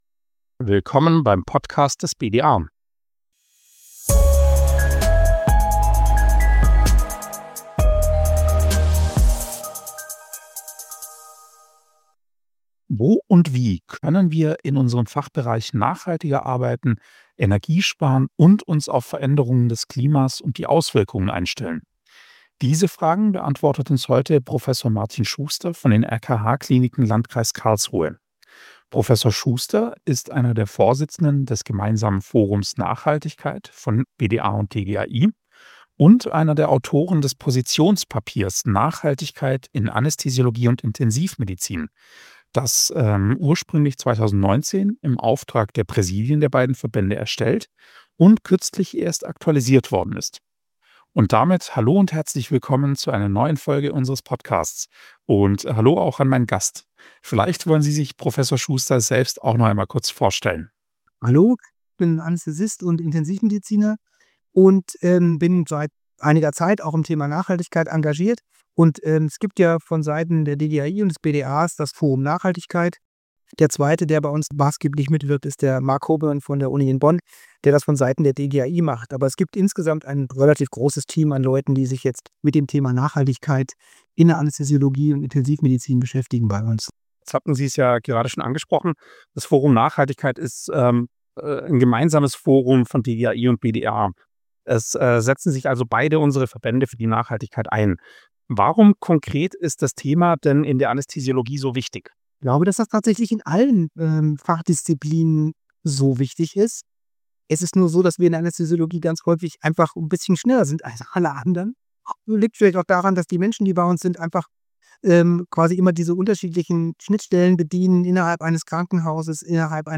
BDA-Podcast Episode #6: Nachhaltigkeit in der Anästhesiologie